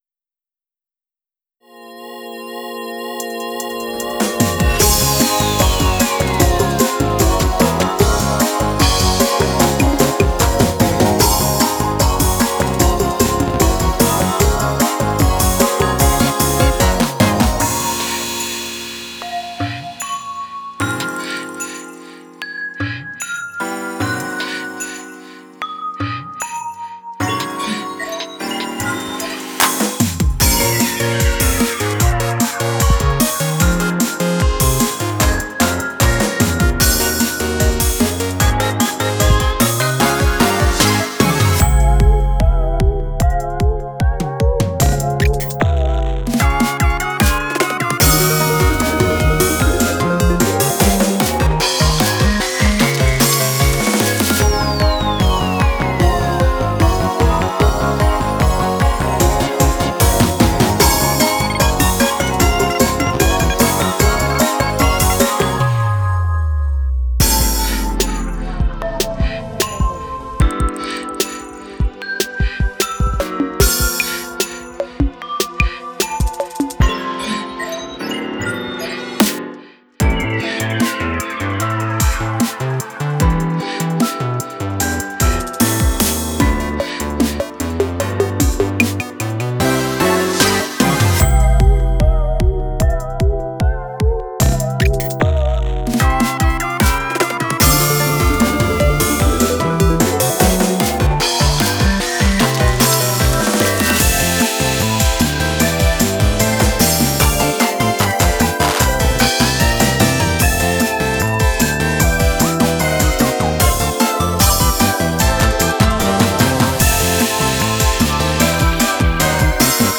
BPMは150です
オケ鑑賞用